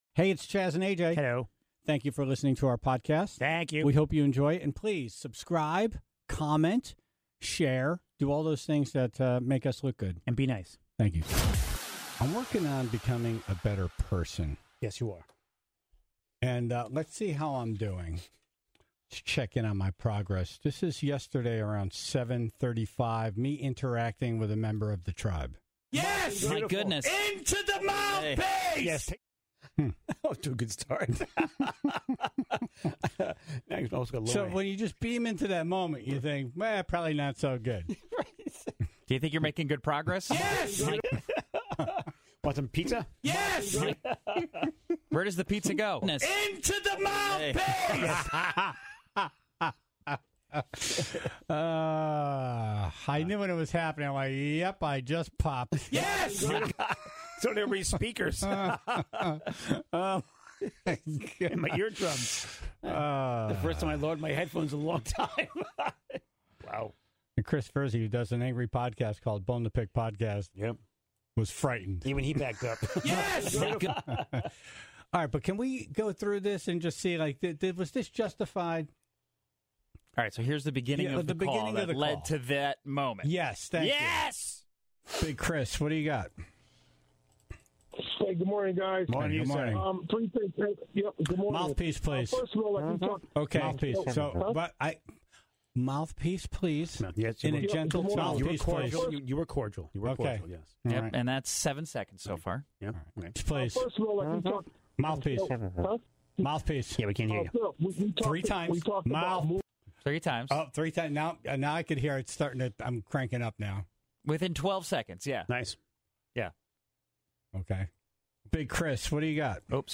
The Tribe offered hundred of options on Facebook, and called in their best golf-related beer names.